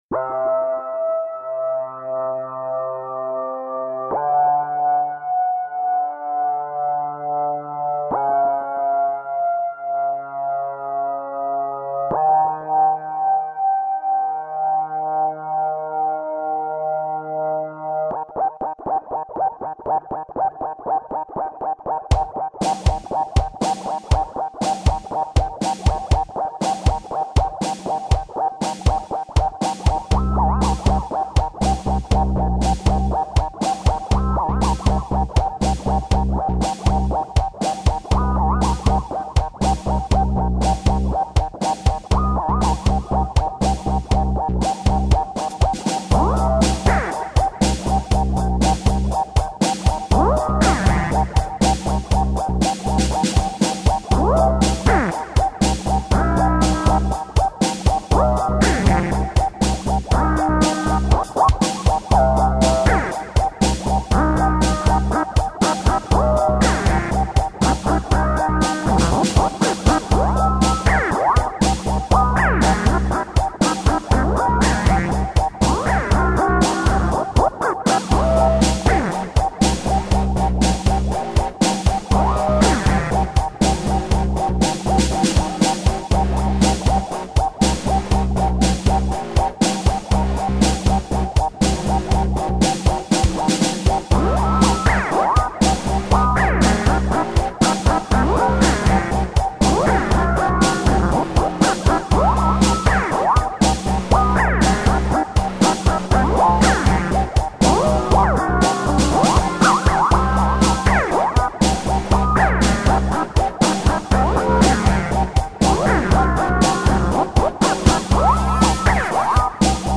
Депресивная музыка.